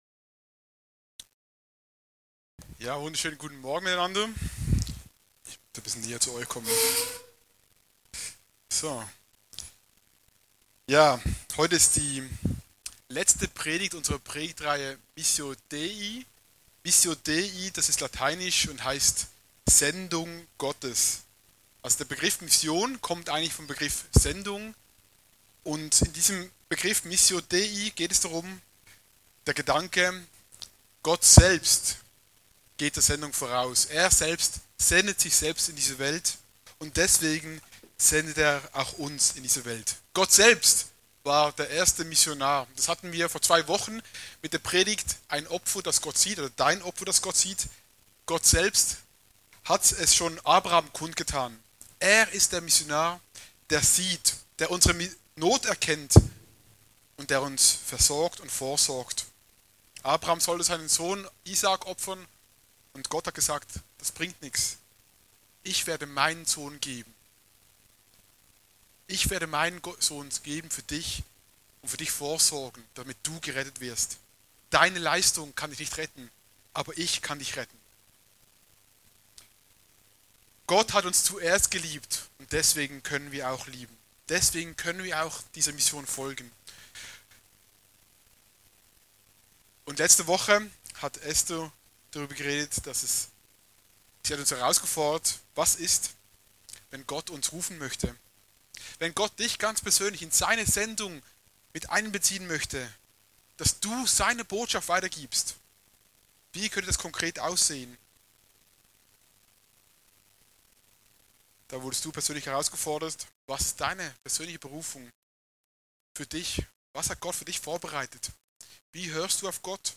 Dritte Predigt der Predigtreihe Missio Dei: Herrlichkeit, die Hoffnung der Welt